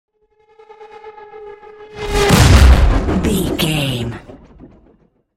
Dramatic chopper whoosh to hit
Sound Effects
Atonal
dark
intense
tension
woosh to hit